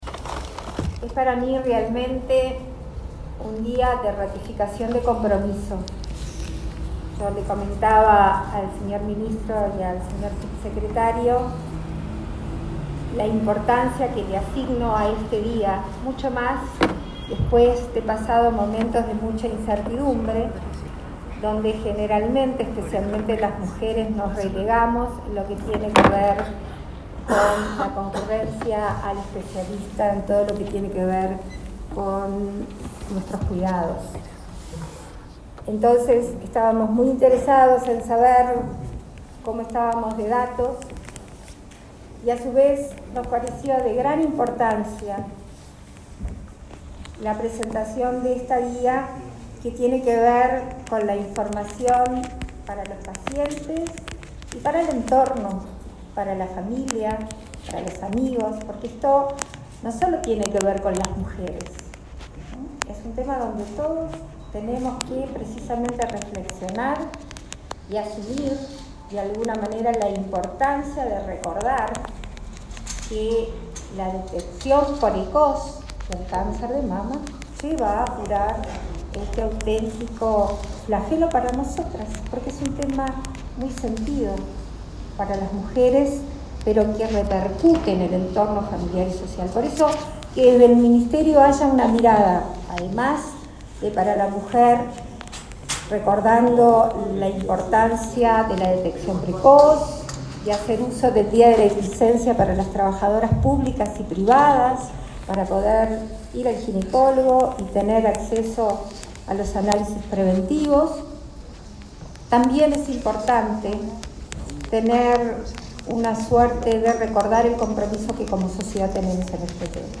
Palabras de la vicepresidenta de la República, Beatriz Argimón
Palabras de la vicepresidenta de la República, Beatriz Argimón 19/10/2021 Compartir Facebook X Copiar enlace WhatsApp LinkedIn La vicepresidenta de la República, Beatriz Argimón, participó, este martes 19, del acto realizado por el Ministerio de Salud Pública, en el Día Mundial de Lucha Contra el Cáncer de Mama.